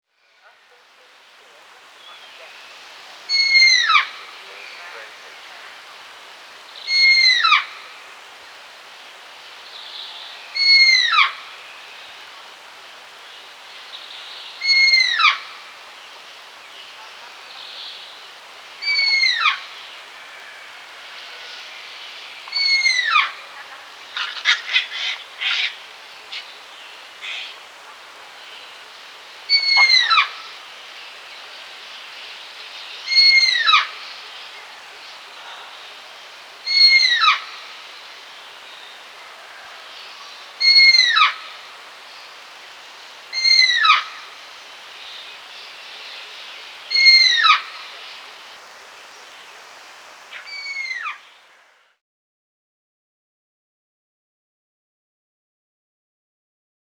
animal
Rock Hyrax Call